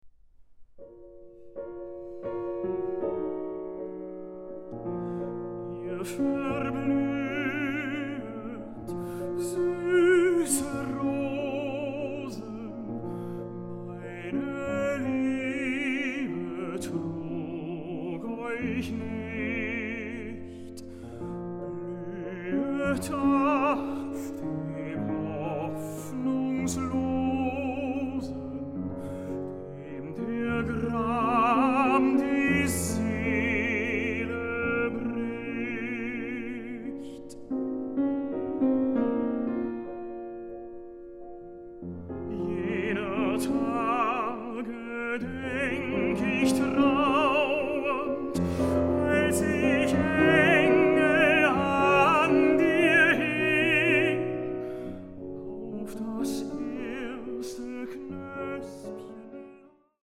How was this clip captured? Recording: Alfried-Krupp-Saal, Philharmonie Essen, 2024